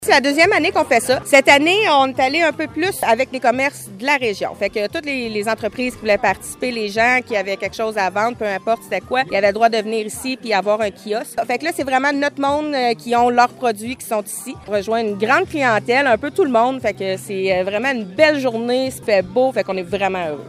La mairesse de Montcerf-Lytton, Véronique Danis, poursuit l’information :